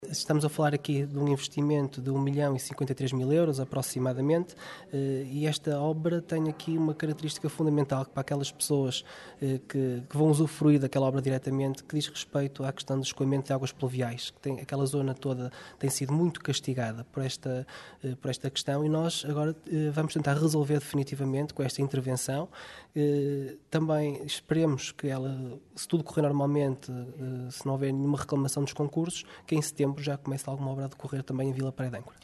Trata-se de um investimento que ultrapassa um milhão de euros, como referiu o vereador responsável pelo pelouro das obras públicas, Rui Lages que estima que a obra poderá arrancar em setembro